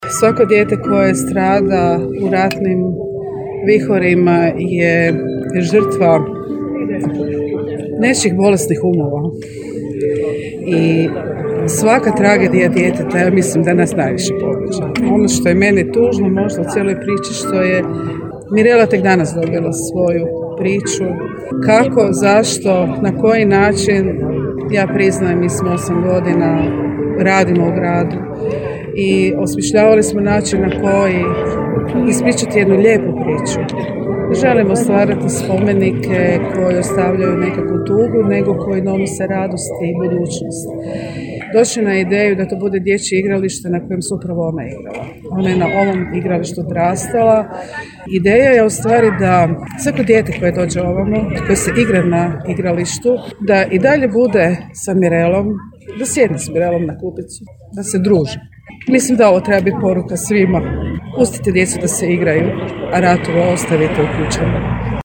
Snažnu poruku s Mirelinog igrališta iz grada za svijet poslala je zamjenica gradonačelnika Daruvara Vanda Cegledi: